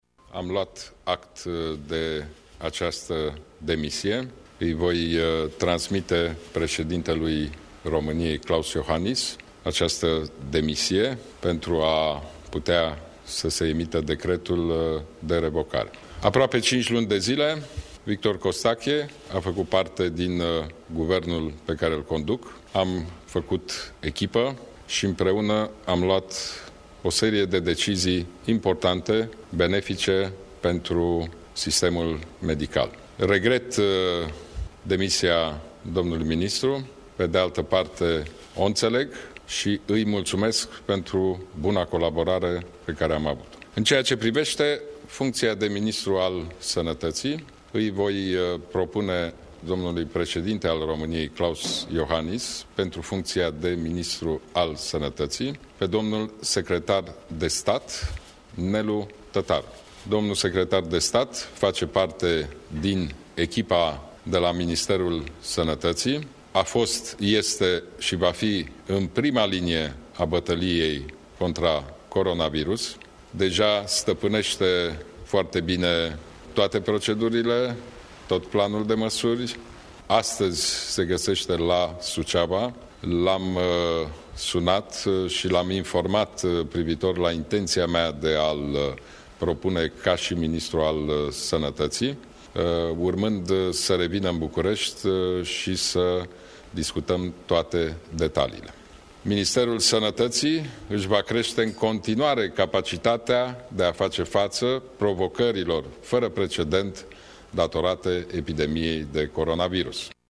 Premierul Ludovic Orban a confirmat informația în urmă cu puțin timp, într-o conferință de presă, iar noua propunere pentru această funcție este secretarul de stat în Ministerul Sănătății, Nelu Tătaru.
Premierul Ludovic Orban: